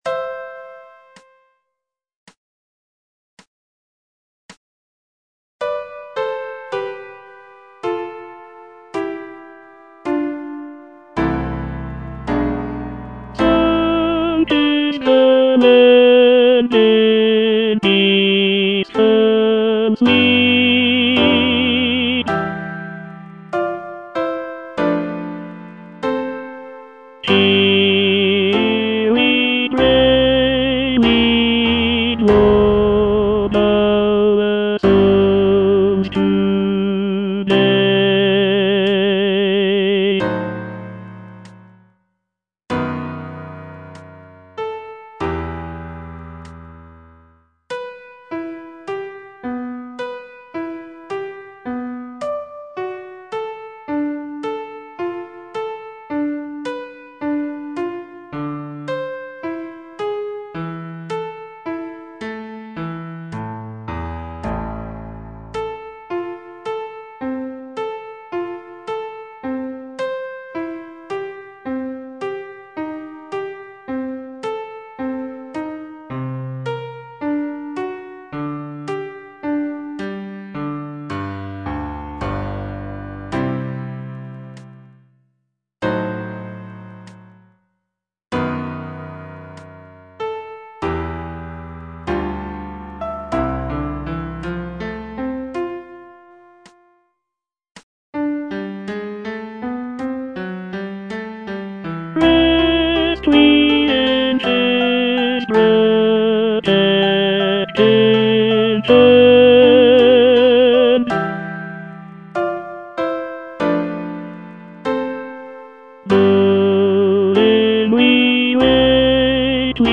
(tenor II) (Voice with metronome) Ads stop